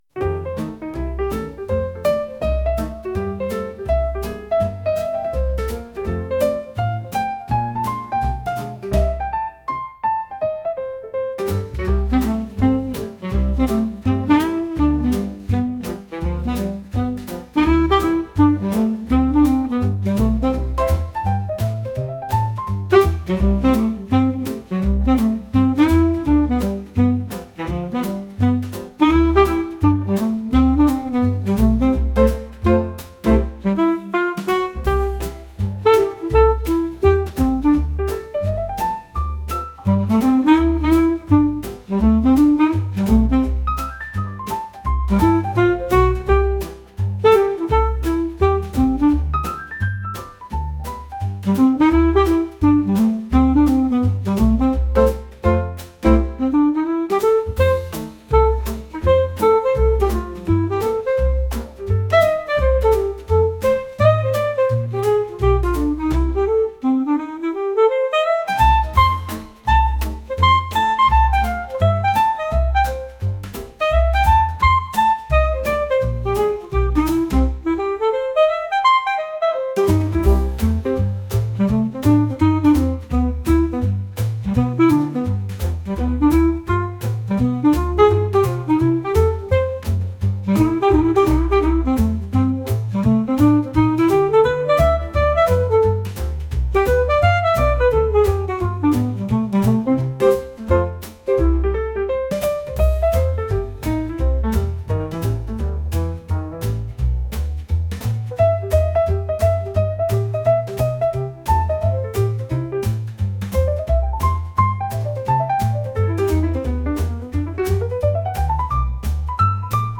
ピアノのスイングジャズ曲です。